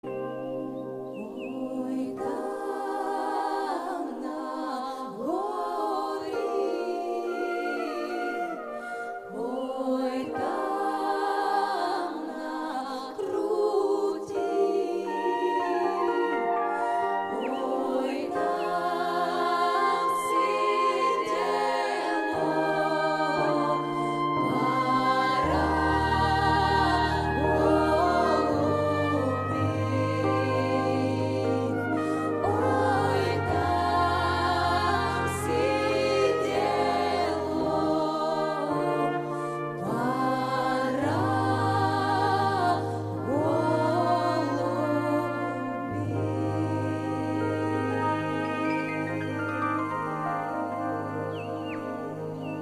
• Качество: 128, Stereo
спокойные
Cover
медленные
фолк
хор